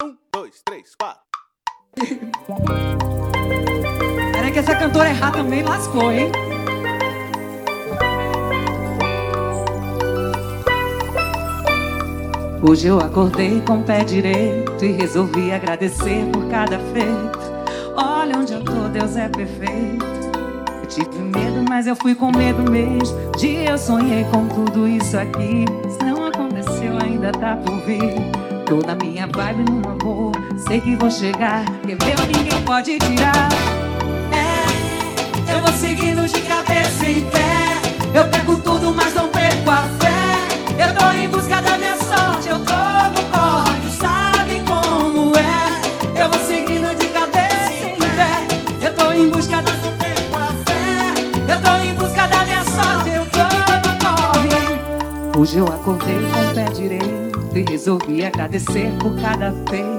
VS DE AXÉ